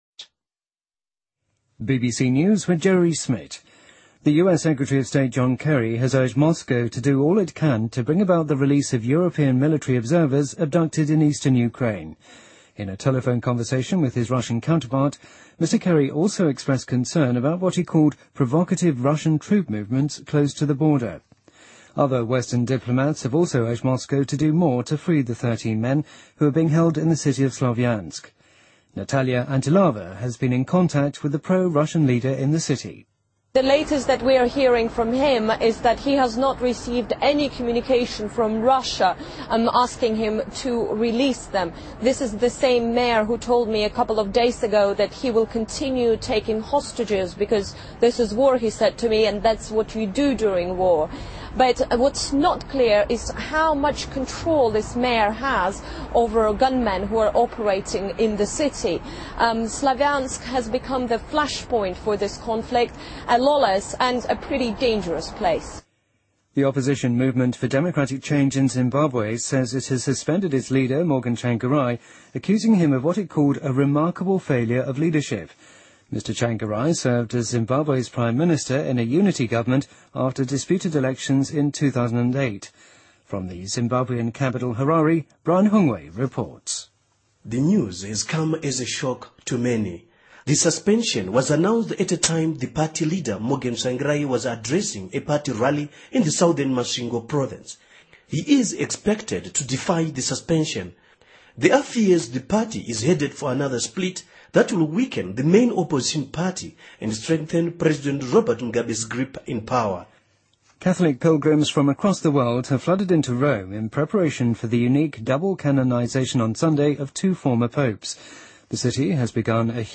BBC news,泰塔尼克号上写的最后一封信在英国拍卖行以20万美元的价格拍卖